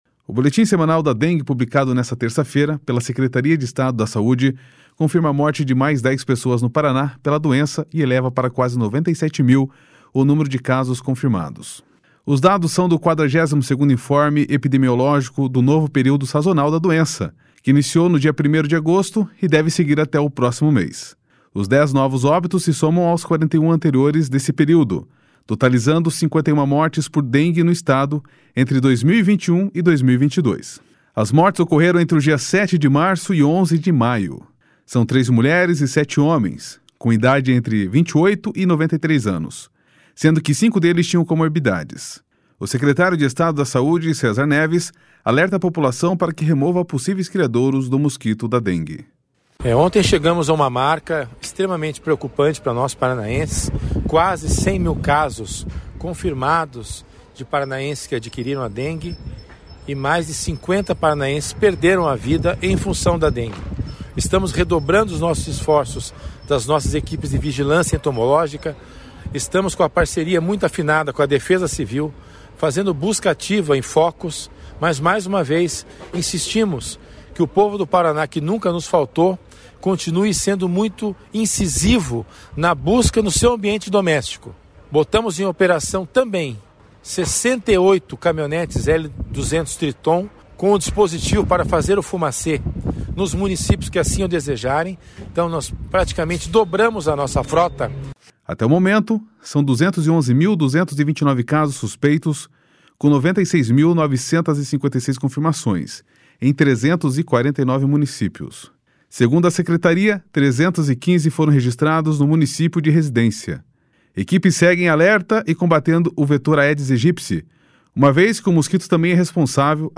O secretário de Estado da Saúde, César Neves, alerta a população para que remova possíveis criadouros do mosquito da dngue. //SONORA CÉSAR NEVES//